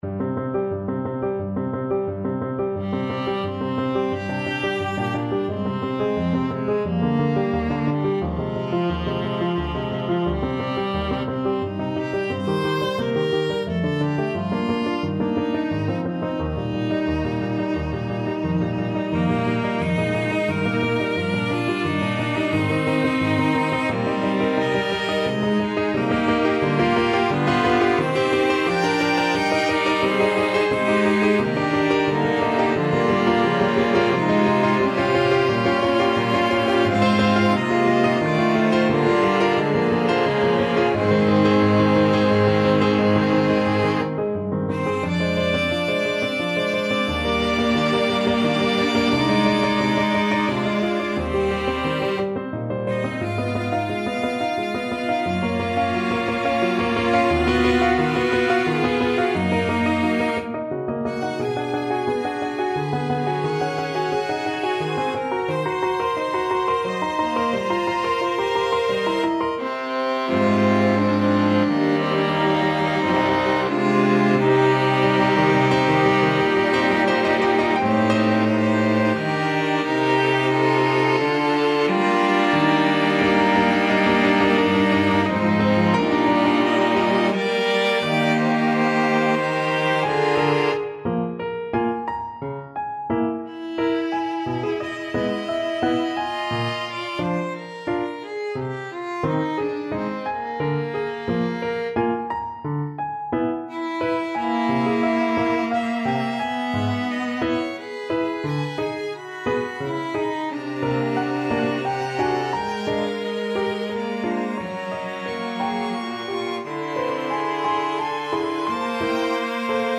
Violin 1 Violin 2 Viola Cello Piano
Key: Eb major (Sounding Pitch)
Tempo Marking: Allegro Moderato = 88
Time Signature: 3/4
Instrument: Piano Quintet
Style: Classical